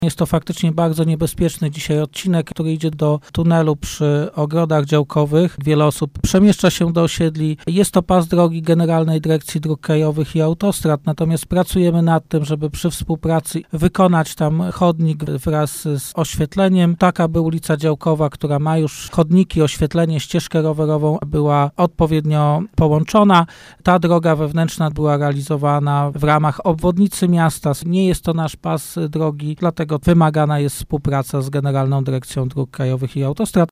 Prezydent Stalowej Woli przyznał, że nie jest to droga należąca do miasta, ale inwestycja jest możliwa do wykonania: